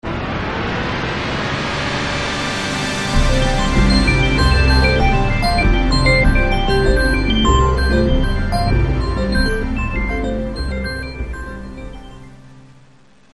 Hier der neue Windows NT-Startsound: Alte Versionen bis Build 1631 verwenden NT 4.0-Startklang
Windows NT 5.0 Build 1671-1906-Startsound